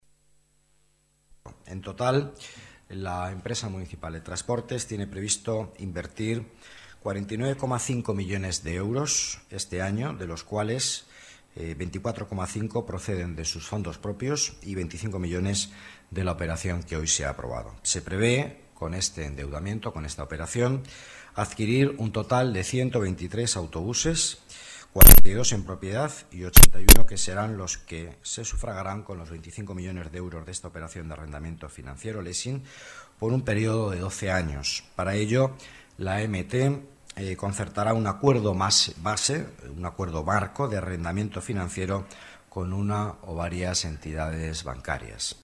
Nueva ventana:Declaraciones del vicealcalde, Miguel Ángel Villanueva